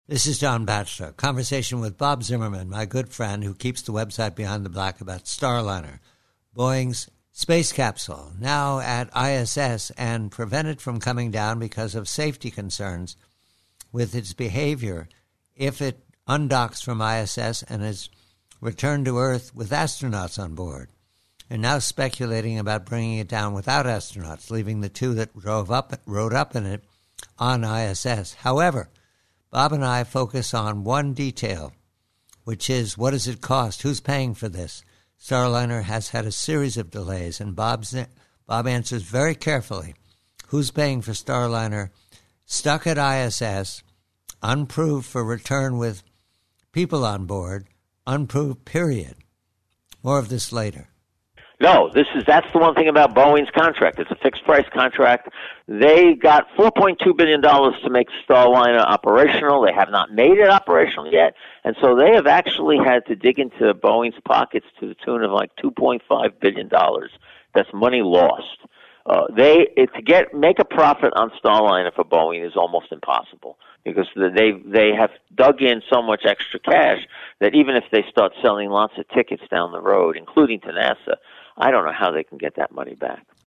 PREVIEW: NASA: BOEING: STARLINER: Conversation